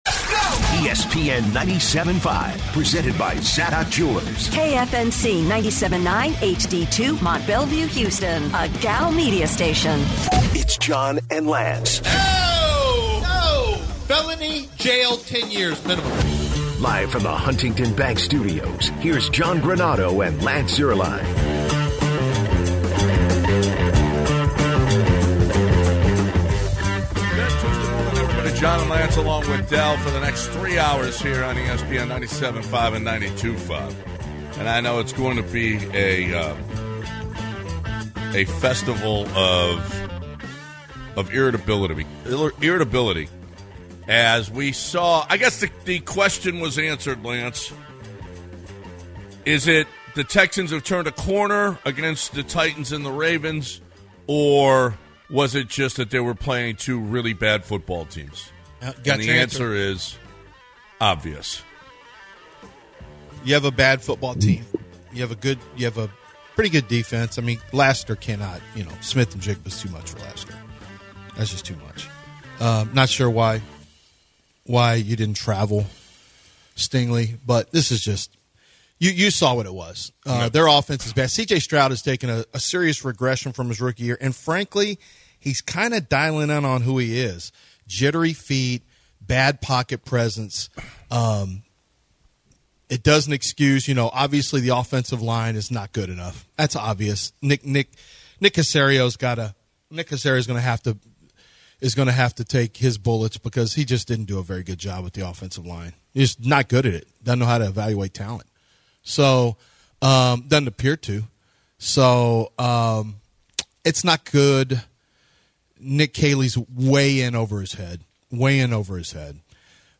10/21 Hour 1-Texans MNF Recap; Callers sound off on loss
Callers talk Texans MNF loss and some World Series chat Texans O-Line, QB play, coaching decisions and running game.